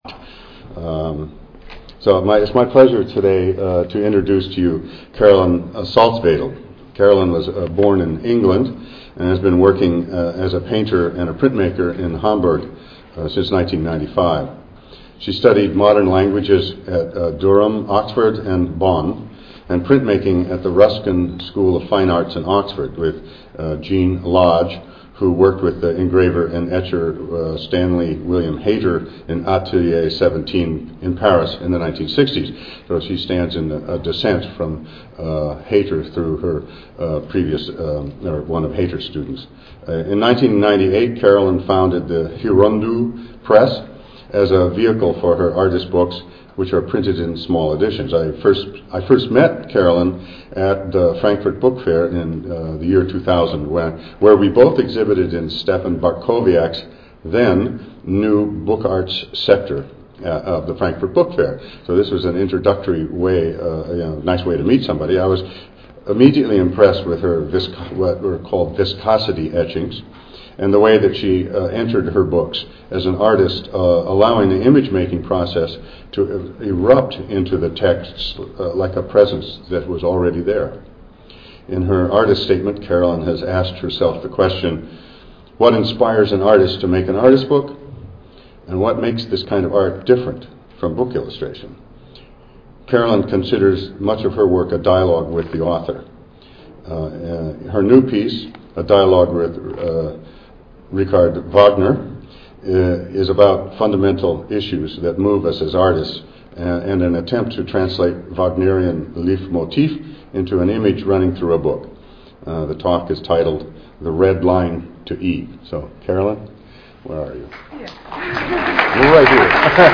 Borders and Collaborations The third biennial CODEX Symposium was held at the Berkeley Art Museum.